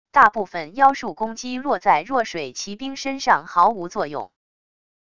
大部分妖术攻击落在弱水骑兵身上毫无作用wav音频生成系统WAV Audio Player